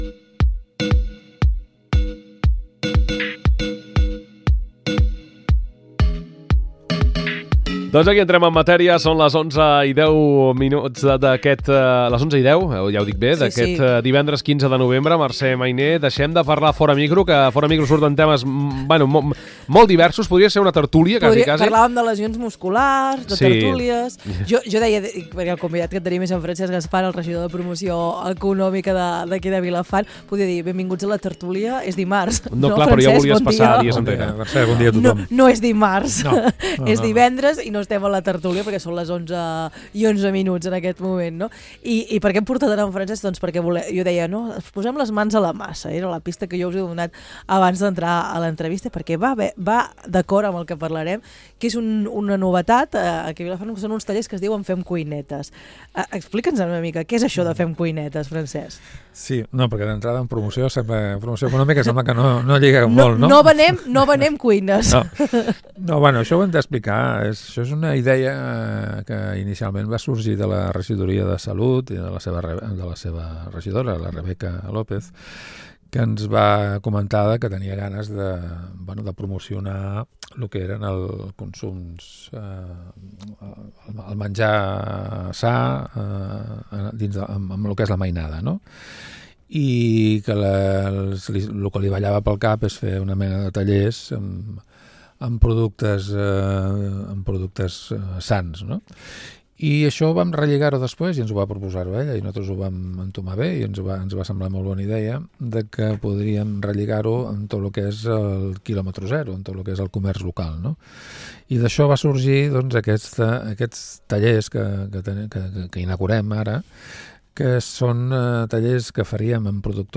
Avui a Les Veus del Matí parlem de Vilafant, amb en Francesc Gaspar, regidor de promoció econòmica parlem de la proposta que han posat en marxa des de les regidories de salut i benestar i promoció econòmica a l’ajuntament, Fem Cuinetes. Una activitat orientada als joves.